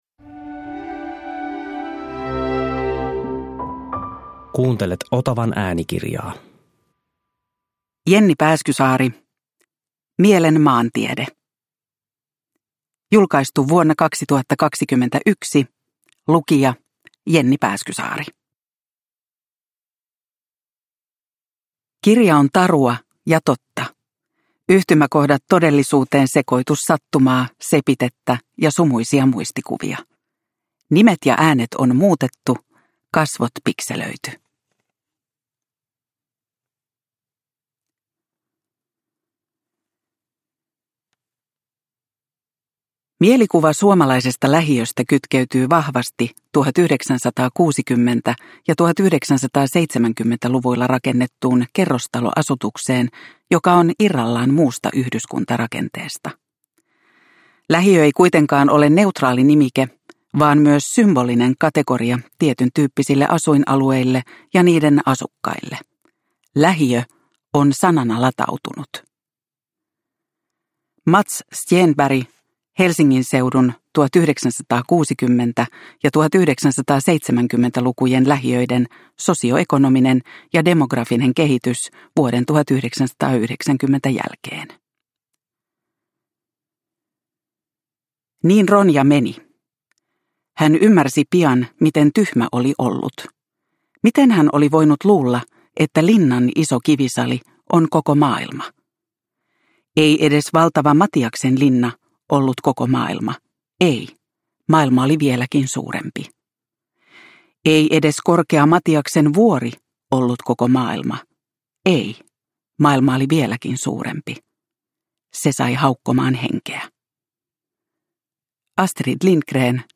Mielen maantiede – Ljudbok – Laddas ner